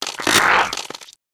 人用力扳东西zth070518.wav
通用动作/01人物/02普通动作类/人用力扳东西zth070518.wav
• 声道 單聲道 (1ch)